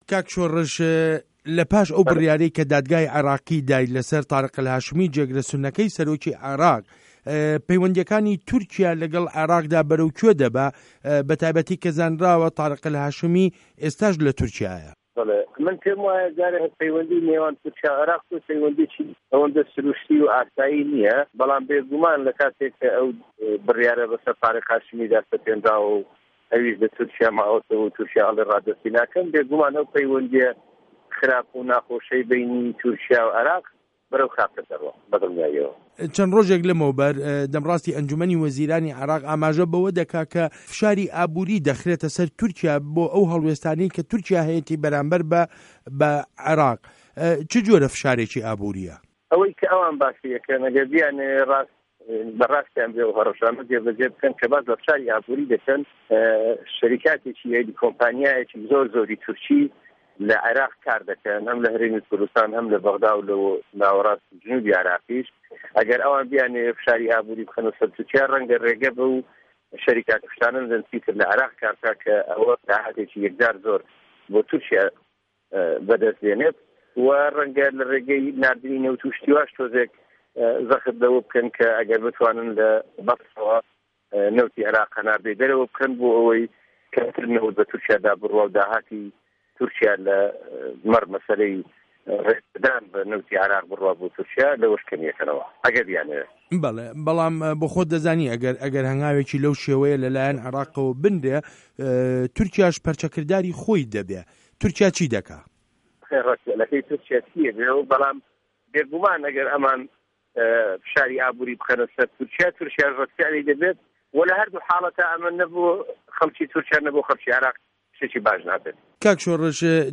وتووێژ له‌گه‌ڵ شۆڕش حاجی